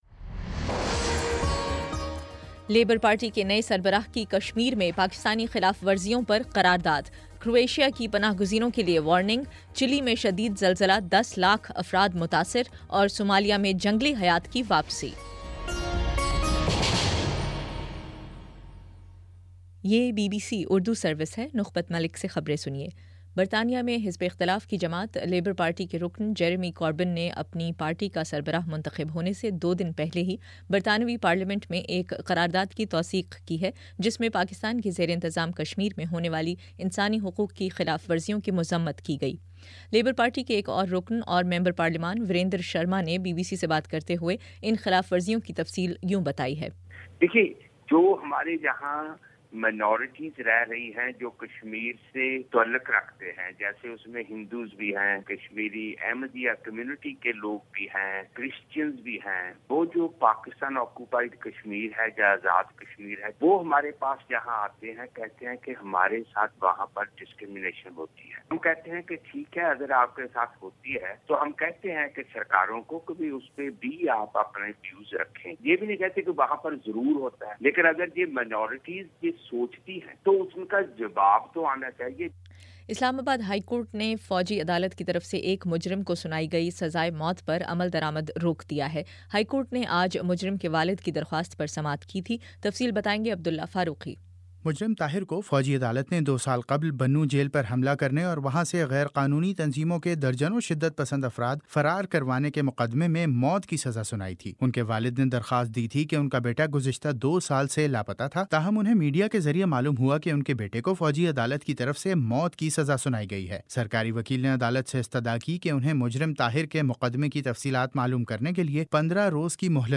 ستمبر17 : شام چھ بجے کا نیوز بُلیٹن